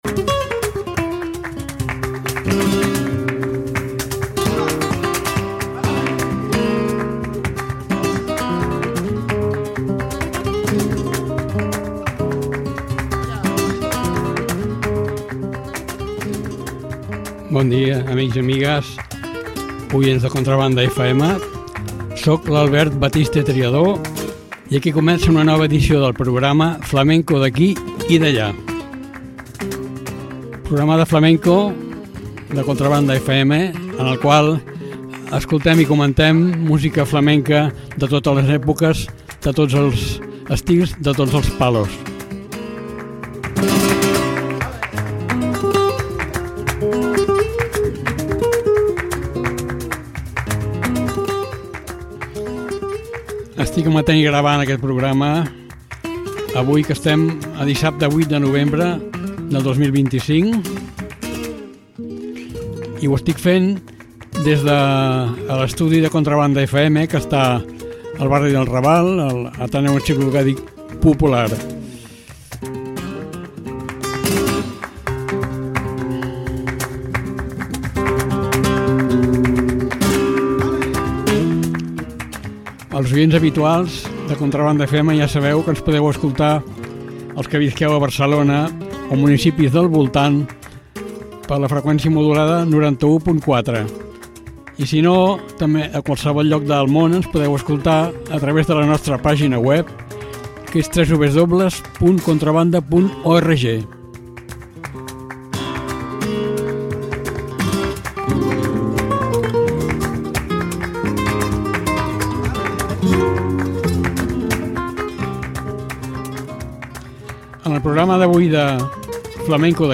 Fiesta por bulerías. Soleá.